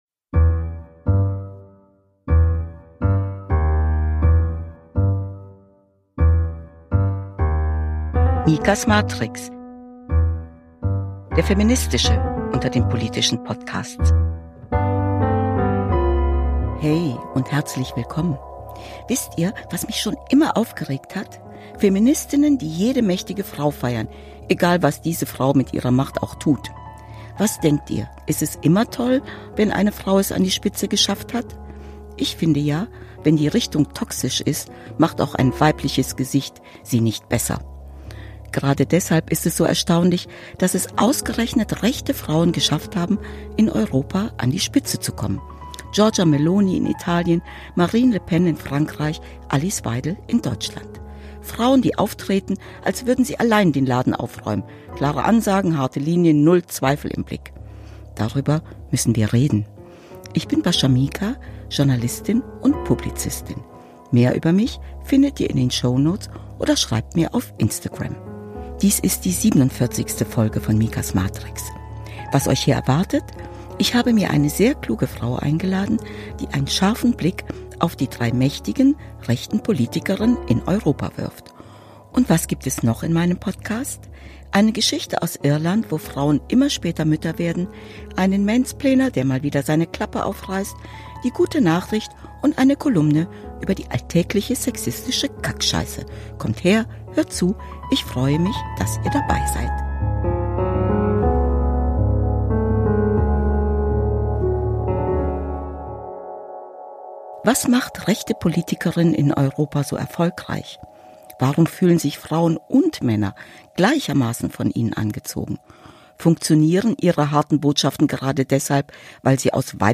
Hier herrscht ein feministischer Blick auf die Politik und kein Platz für Ignoranz. Bascha Mika stellt sich streitbar diskussionsfreudigen Männern des Patriarchats entgegen. In intensiven Gesprächen mit klugen Gästen wird der laute Ruf des Feminismus gepflegt.